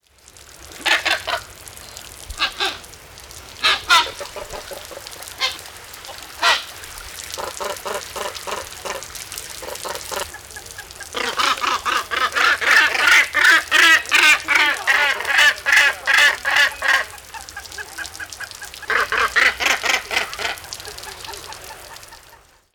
Basstölpel
Kontaktlaute einer Basstölpelgruppe
215-basstoelpel_gruppe_kontaktlaute-soundarchiv.com_.mp3